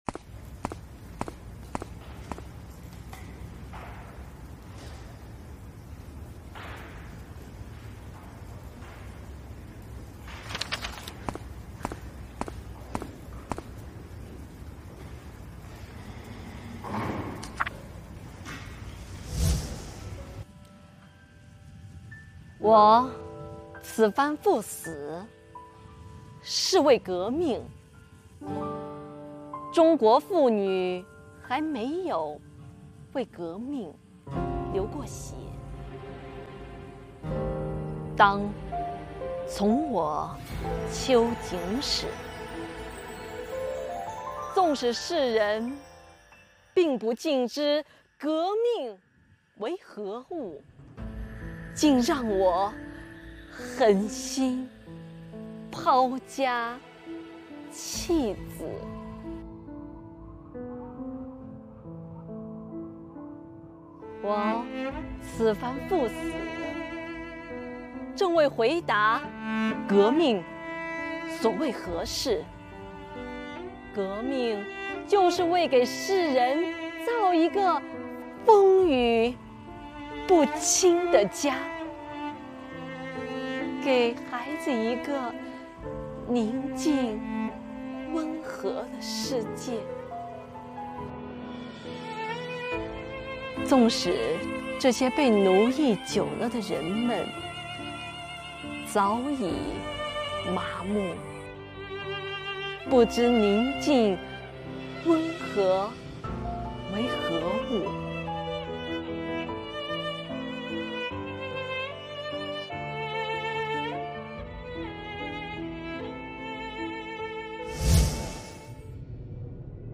诵读征集活动优秀作品